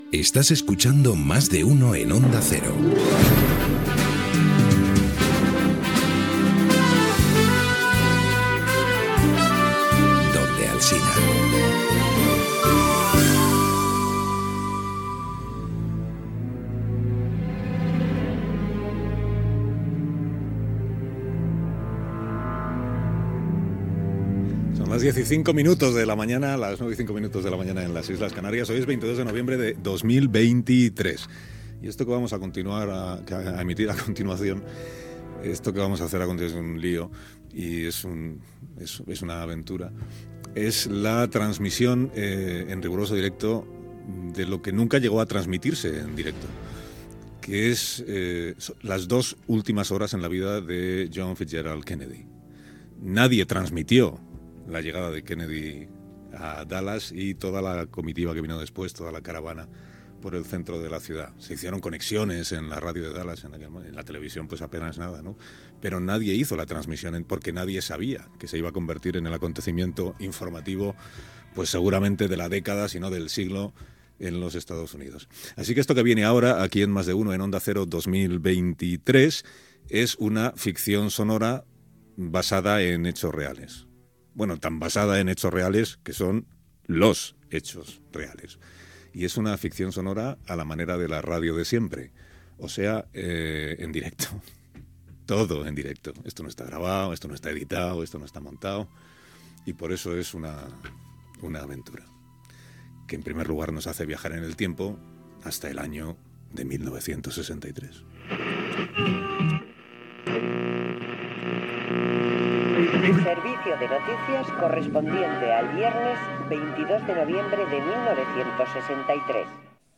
Indicatiu del programa, hora, presentació de l'espai i ficció sonora sobre les dues darreres hores en la vida de John Fitzgerald Kennedy, el 22 de novembre de 1963.
Info-entreteniment